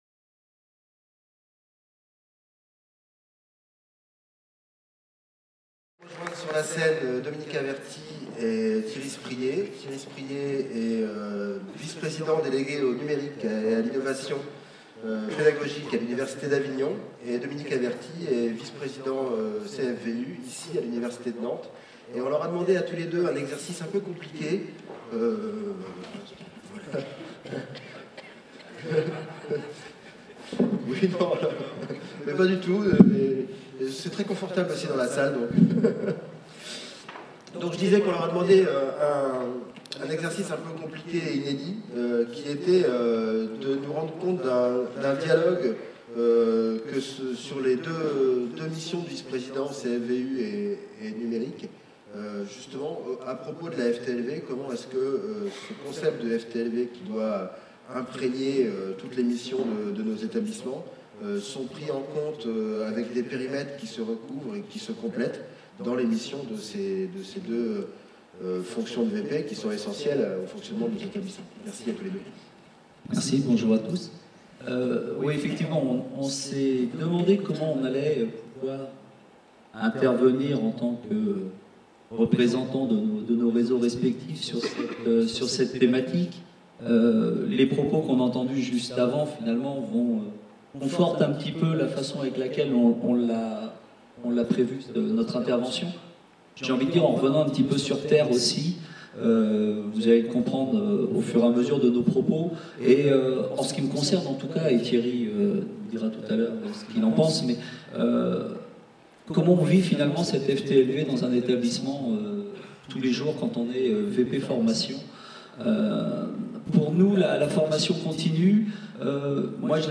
4ème rencontre : coorganisée le 5 décembre 2017, par la DGESIP et l'université de Nantes au Stéréolux (Ile de Nantes). Cycle de journées de rencontres sur les nouveaux modèles pour la F.T.L.V. 1er modèle : les effets du numérique sur l'organisation du travail, les réponses de l'enseignement supérieu.